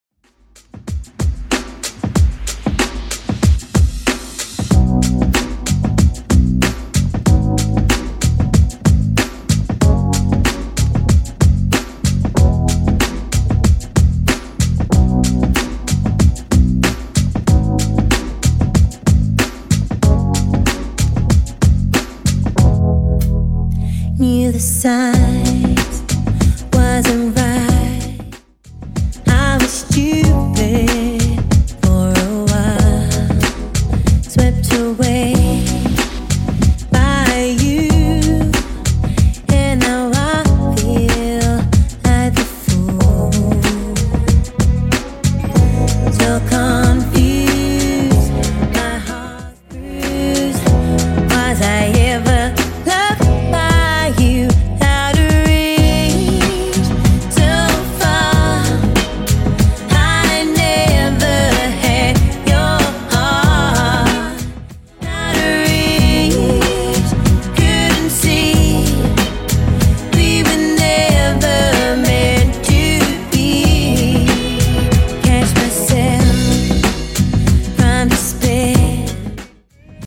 Genres: 60's , RE-DRUM Version: Clean BPM: 132